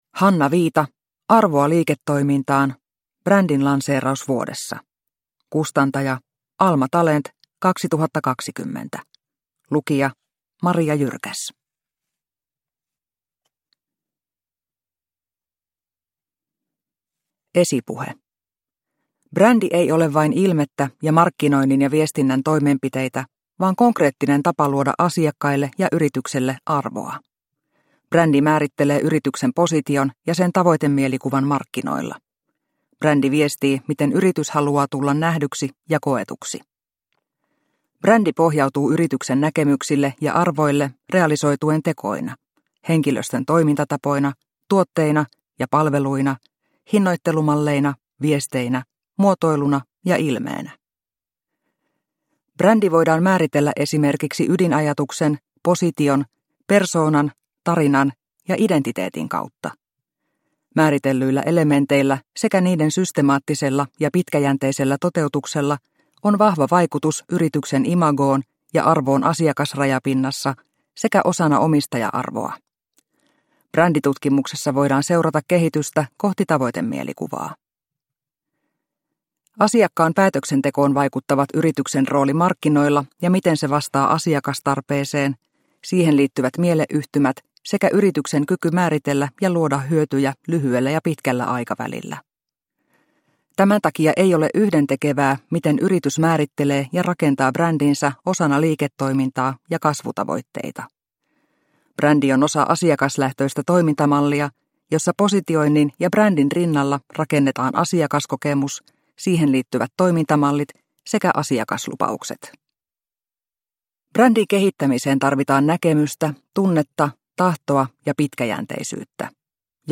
Arvoa liiketoimintaan – Ljudbok – Laddas ner